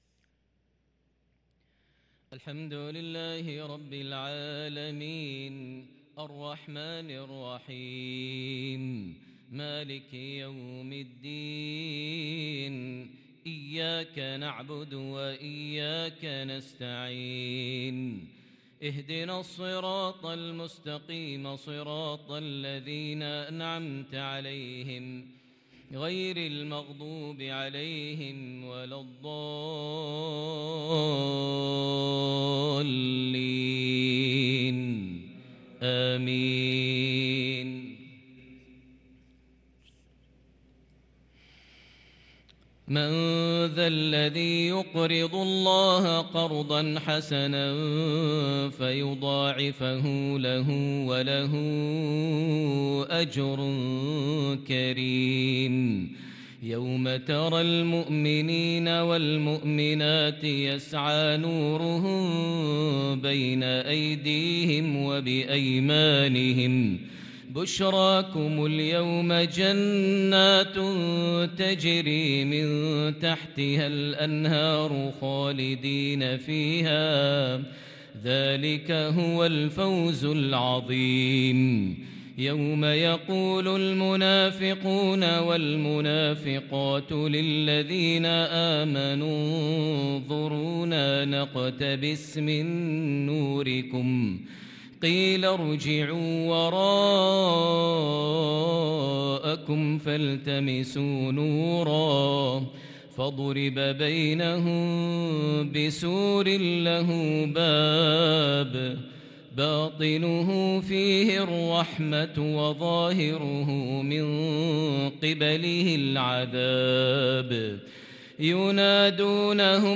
صلاة العشاء للقارئ ماهر المعيقلي 8 صفر 1444 هـ
تِلَاوَات الْحَرَمَيْن .